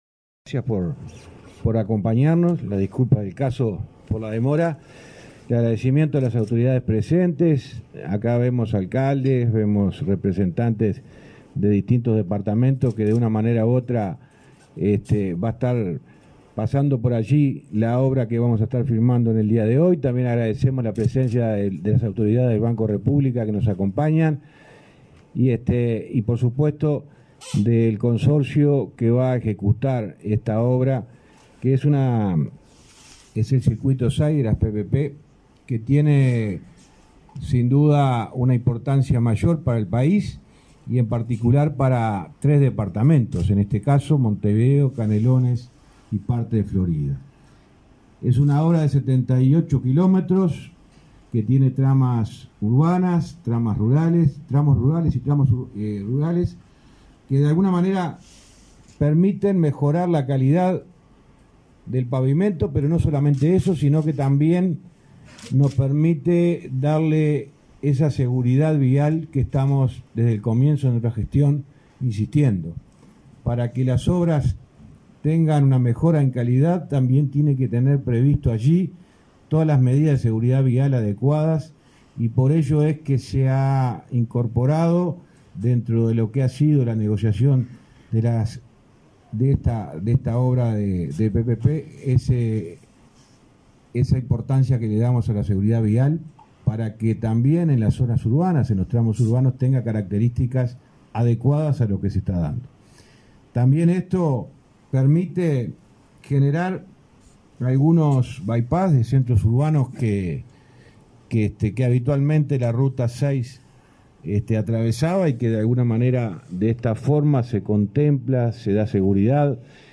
Conferencia de prensa por la firma de contrato para obras en ruta 6